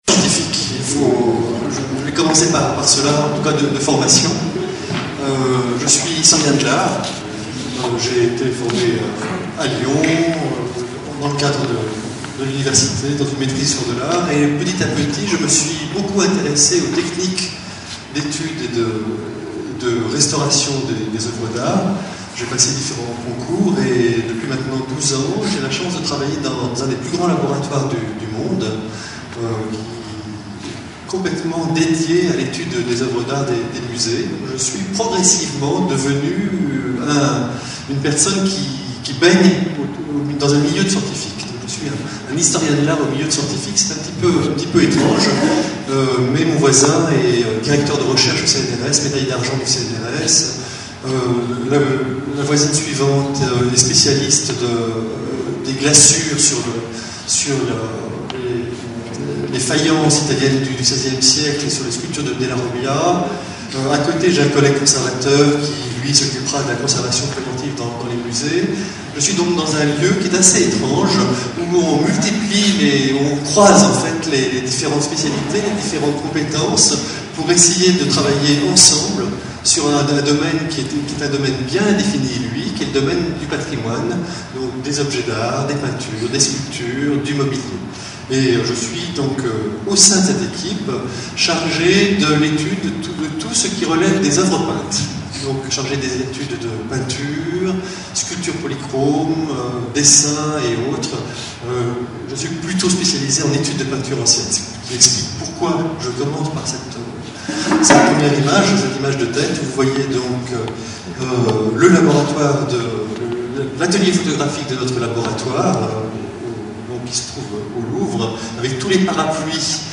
Une conférence de l'UTLS au Lycée Art et science